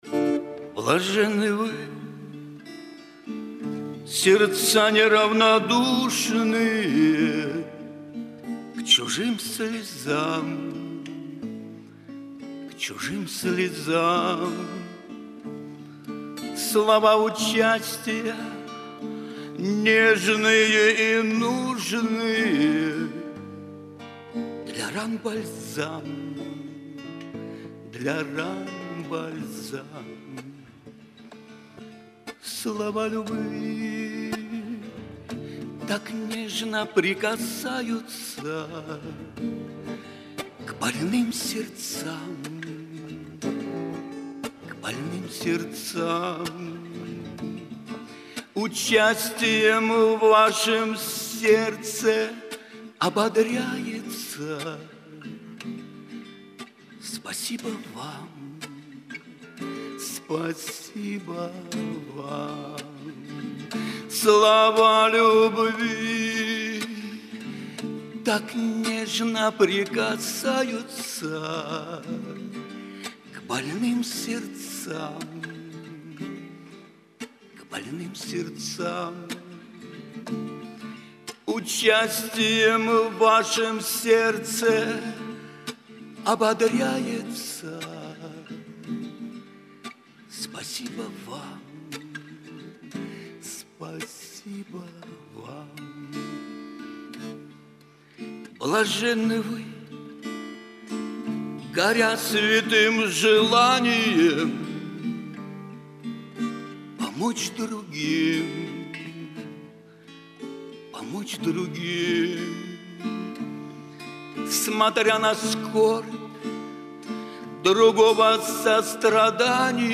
Богослужение 04.01.2023
Пение